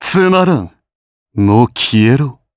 Shakmeh-Vox_Skill6_jp.wav